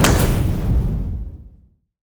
flamethrower-end-1.ogg